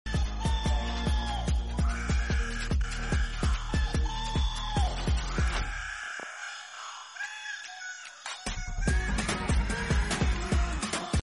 İstanbul’da hafta sonu düzenlenen Manifest konserinde sıra dışı bir an yaşandı.
Kalabalığın bir kısmı sahnedeki slogana karşılık verirken, diğer kısmı ise sessiz kaldı.